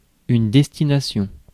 Ääntäminen
IPA: [dɛs.ti.na.sjɔ̃]